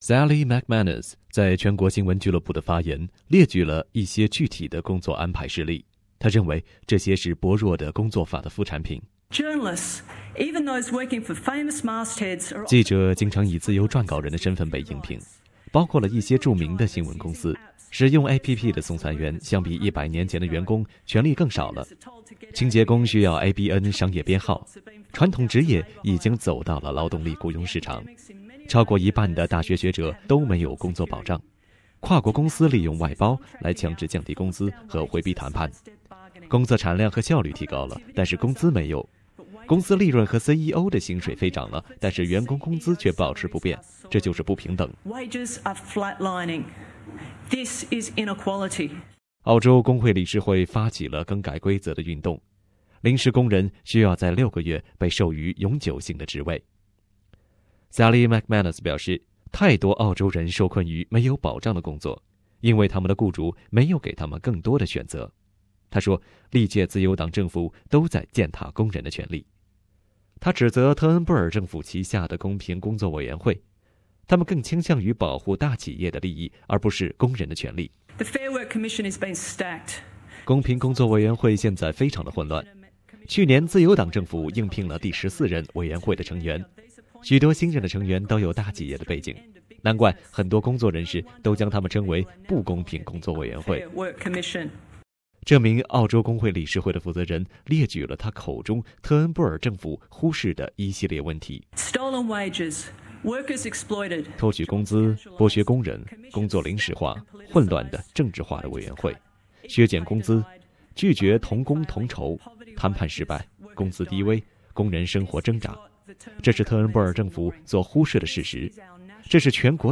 Source: AAP SBS 普通話電台 View Podcast Series Follow and Subscribe Apple Podcasts YouTube Spotify Download (1.29MB) Download the SBS Audio app Available on iOS and Android 工會運動的負責人指責稱，澳洲工作法是不公正現象上升的原因之一。